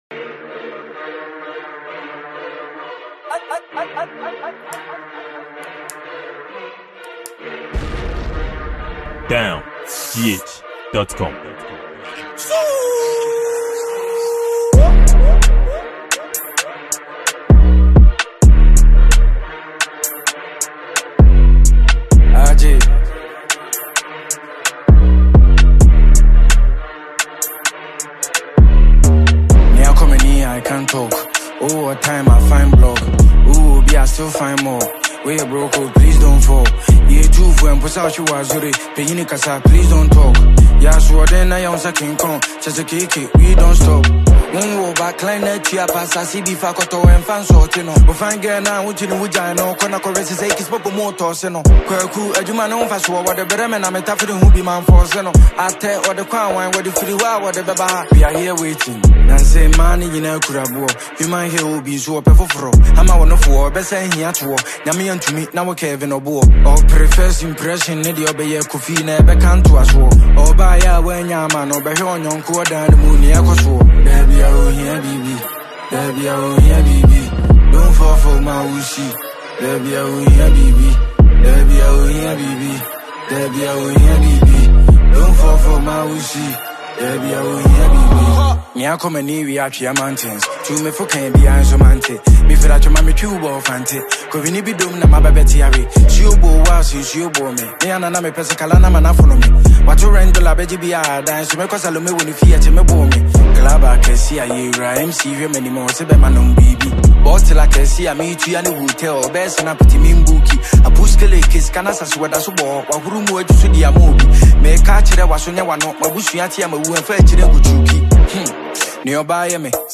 It’s hiphop genre.